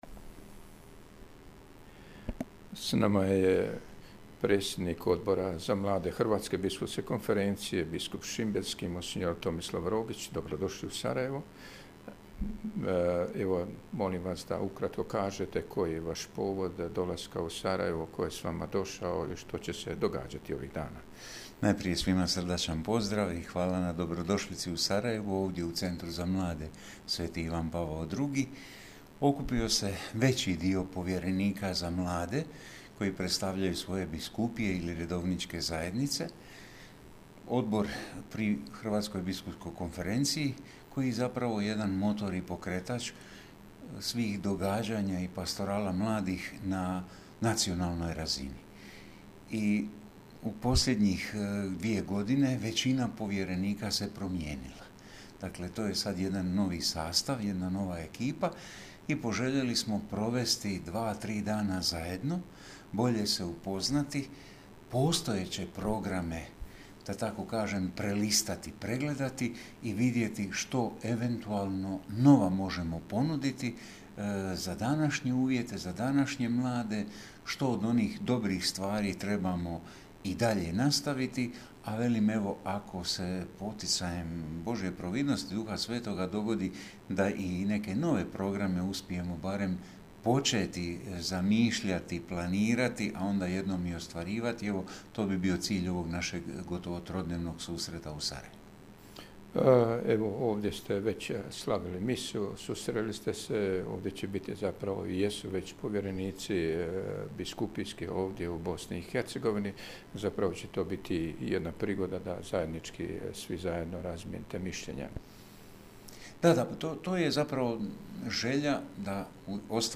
Susret je započeo Misnim slavljem koje je u kapeli Centra predvodio nadbiskup Vukšić u zajedništvu s biskupom Rogićem i uz koncelebraciju svih povjerenika.